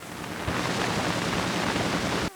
TAPE_NOISE_01.wav